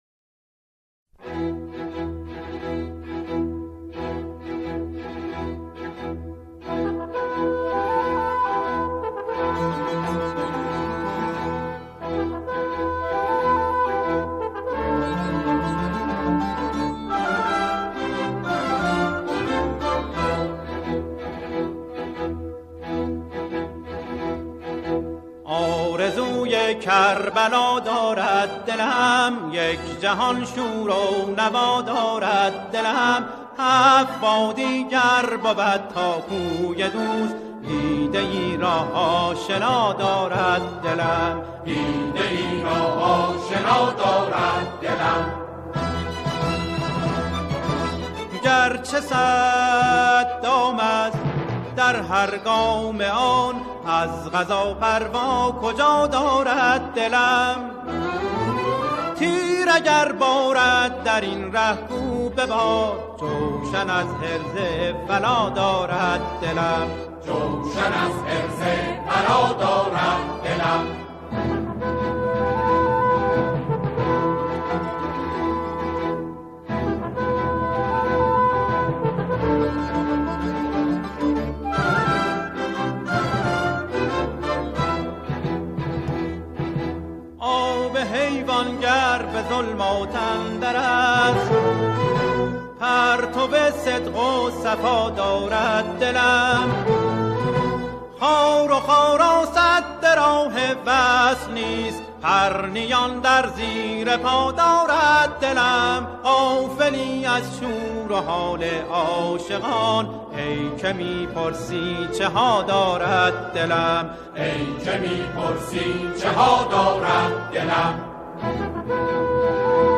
اربعین